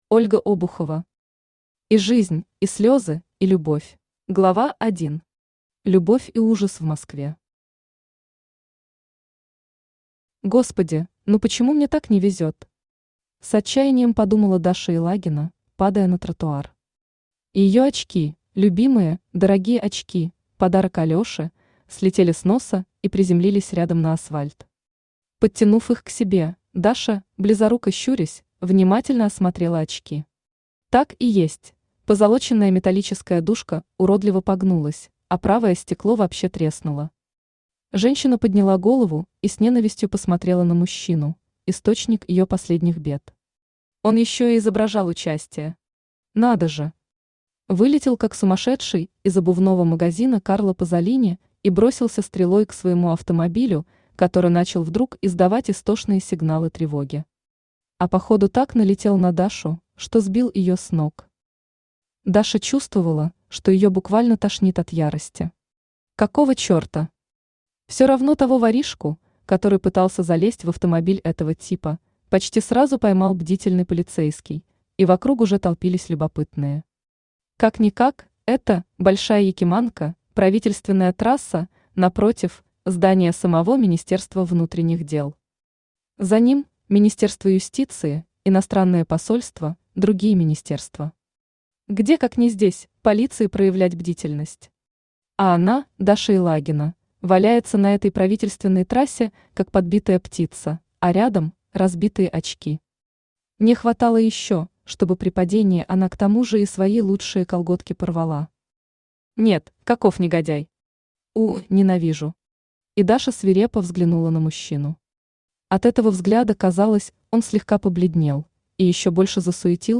Аудиокнига И жизнь, и слезы, и любовь | Библиотека аудиокниг
Aудиокнига И жизнь, и слезы, и любовь Автор Ольга Ивановна Обухова Читает аудиокнигу Авточтец ЛитРес.